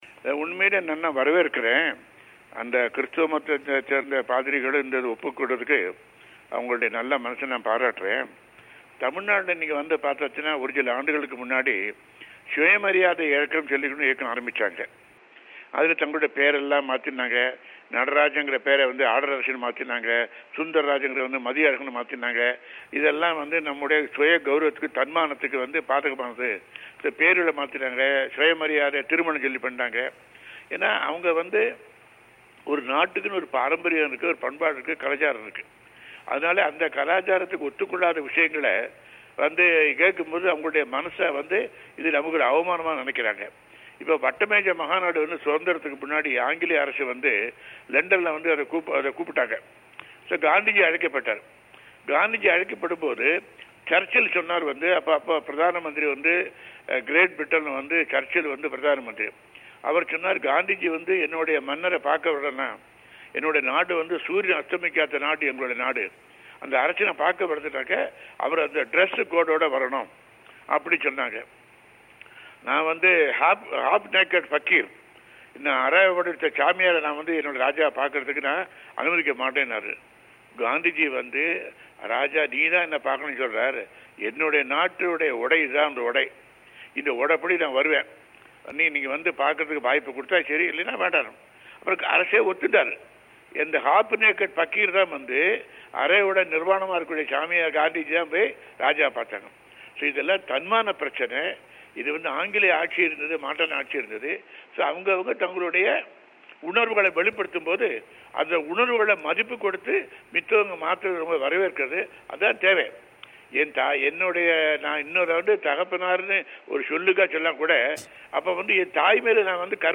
தமிழோசைக்கு வழங்கிய பேட்டி.